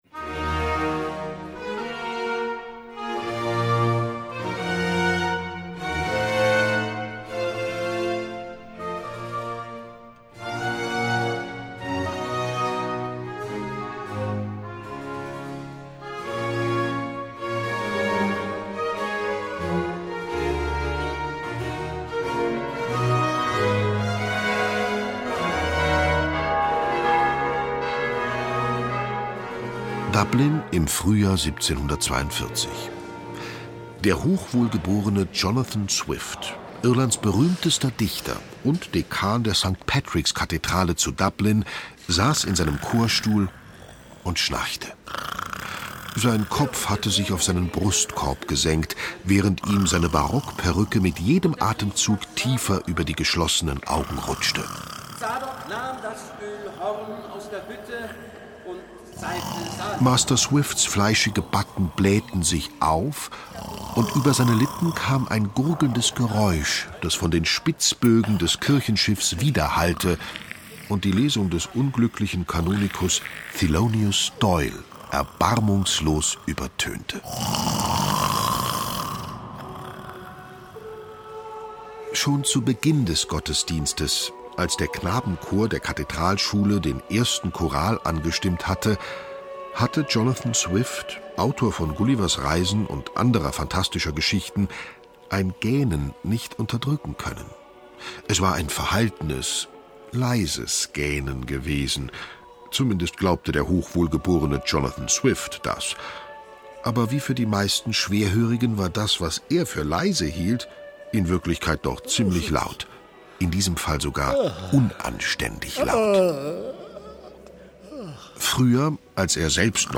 Schlagworte Der Messias (Händel) • Händel, Georg Friedrich • Händel, Georg Friedrich; Kindersachbuch/Jugendsachbuch • Händel, Georg Fr.; Kindersachbuch/Jugendsachbuch • Hörbuch; Lesung für Kinder/Jugendliche • Klassische Musik • Messias